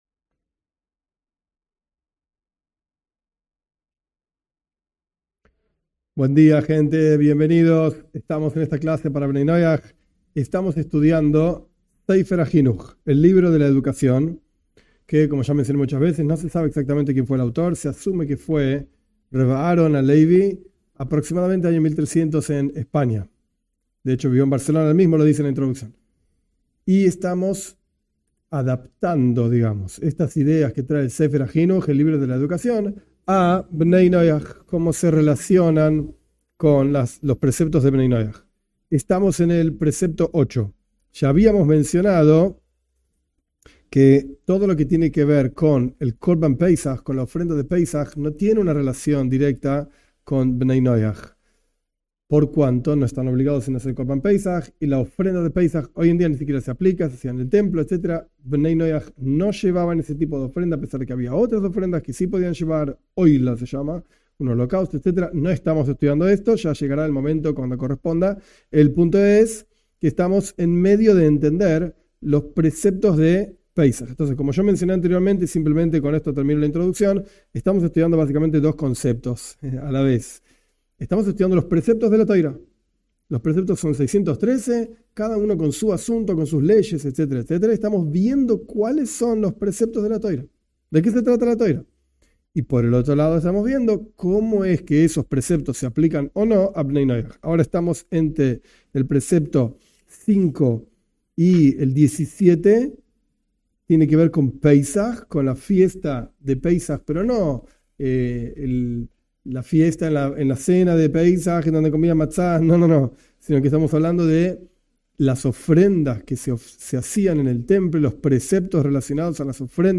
En este curso estudiaremos los preceptos del judaísmo en forma breve, basándose en el libro de la educación (Sefer HaJinuj) y aplicándolos a Bnei Noaj de acuerdo a los escritos de Rabí Ionatan Steiff (1877-1958). En esta clase estudiamos los preceptos relacionados a Pesaj.